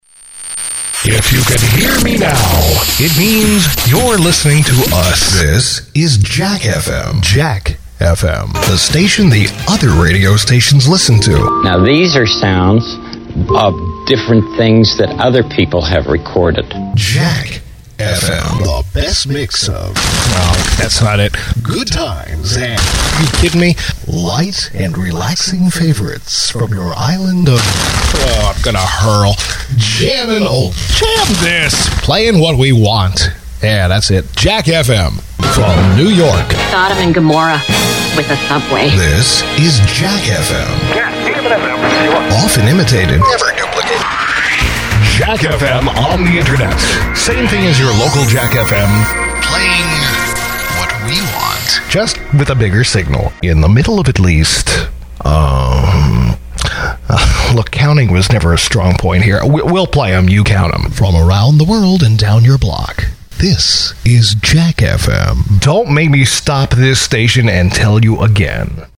VOICEOVER DEMOS
Imaging Demo
Broadcast-quality home studio with digital delivery; ISDN with 24-hour notice.